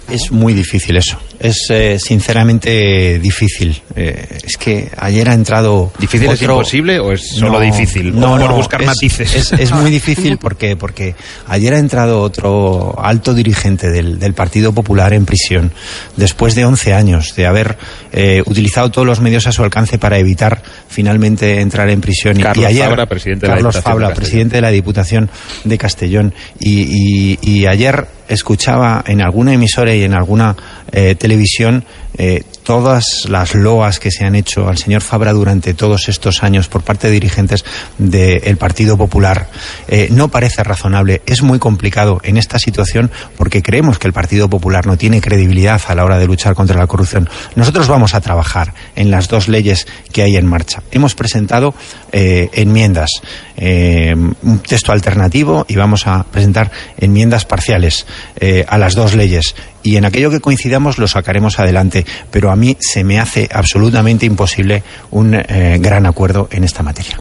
Fragmento de la entrevista de Antonio Hernando en Las mañanas de RNE en el que explica por qué es muy difícil pactar nada con el PP sobre la lucha contra la corrupción 3/12/2014